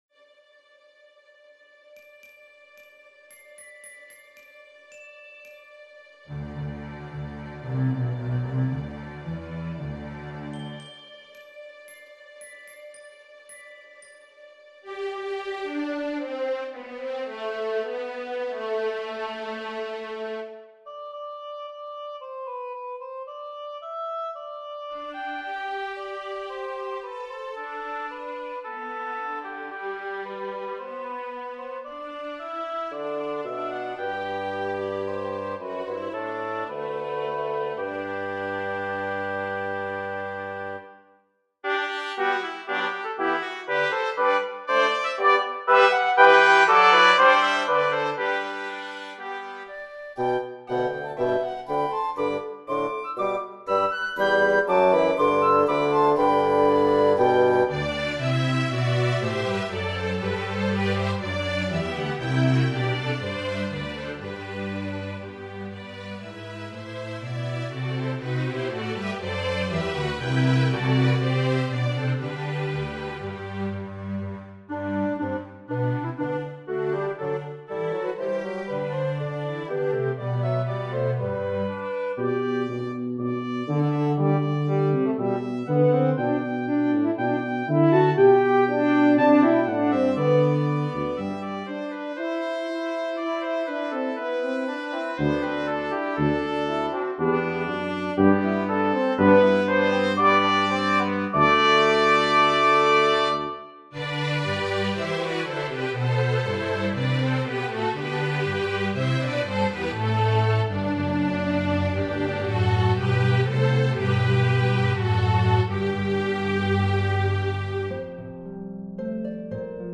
Orchestral Arr.